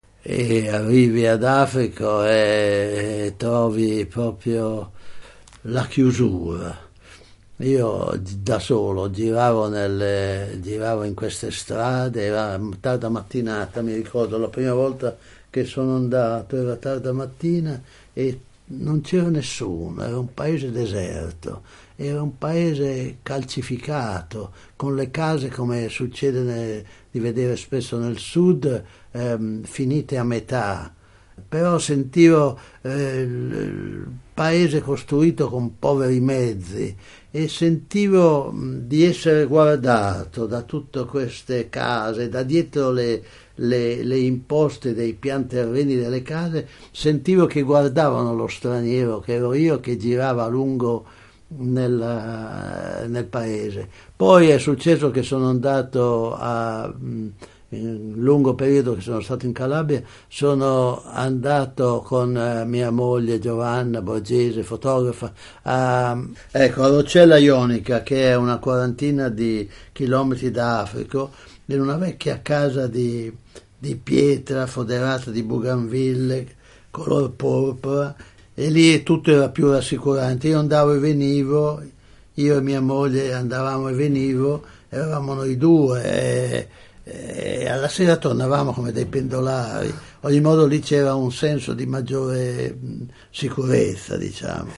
Ascolta Corrado Stajano raccontare cosa vide arrivando per la prima volta ad Africo.